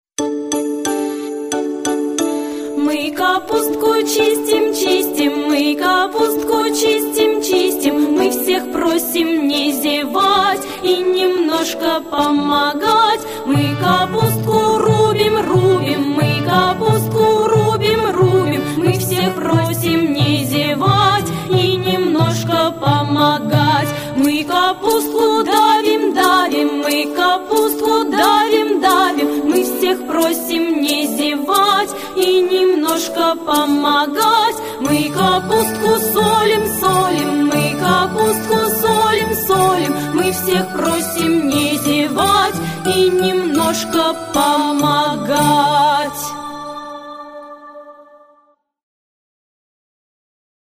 Засолка капусты - песенка с движениями - слушать онлайн